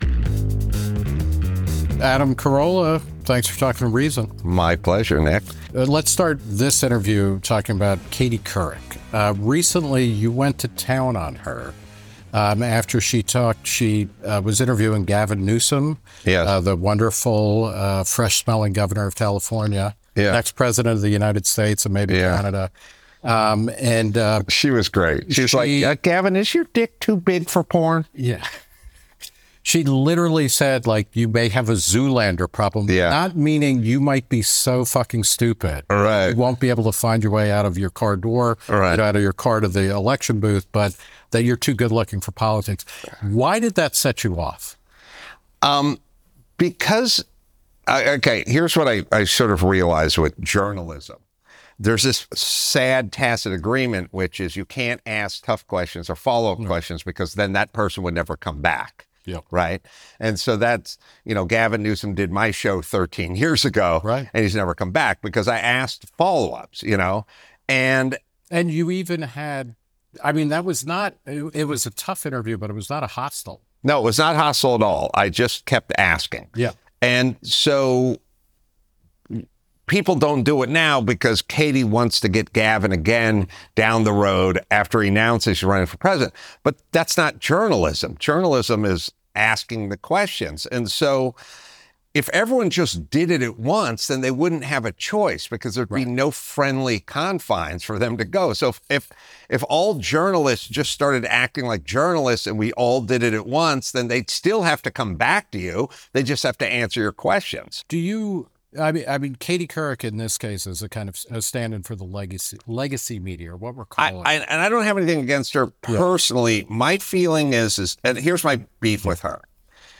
Comedian Adam Carolla discusses how soft journalism destroys media credibility, why California is losing residents, and the importance of meritocracy.
The Reason Interview With Nick Gillespie